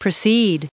610_preceded.ogg